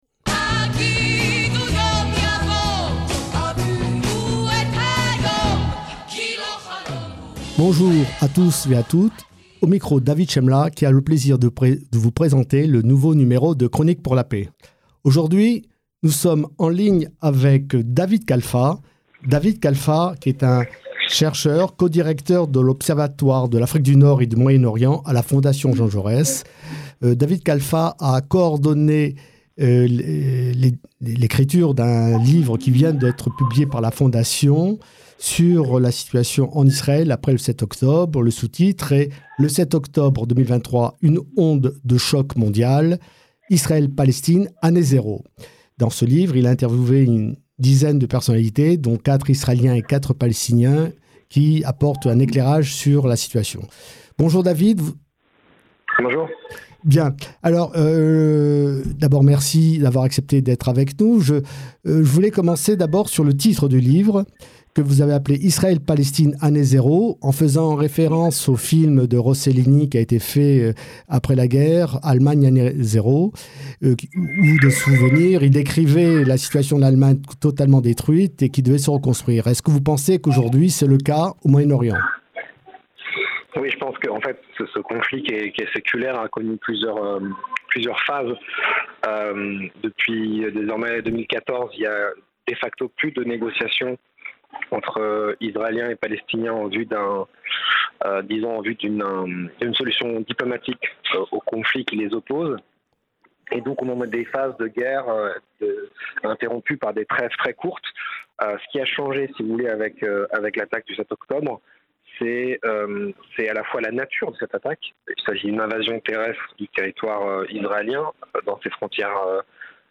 émission bimensuelle sur Radio Shalom